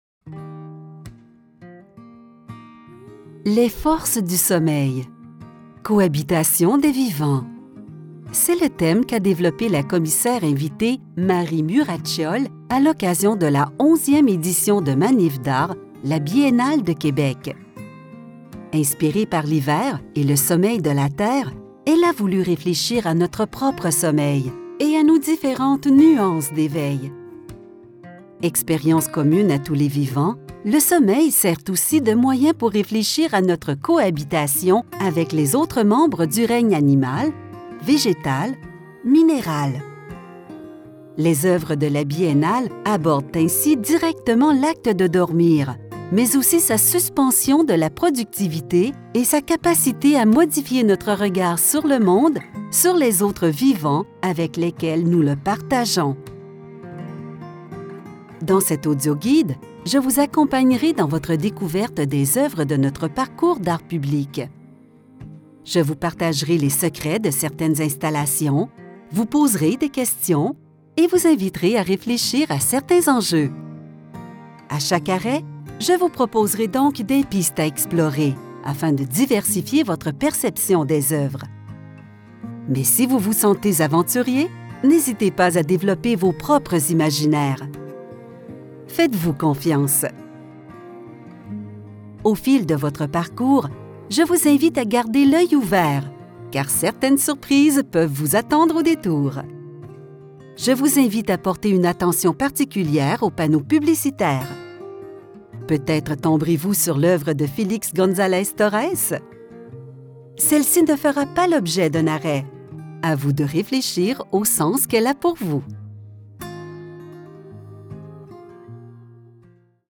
Accessible, Reliable, Corporate
Audio guide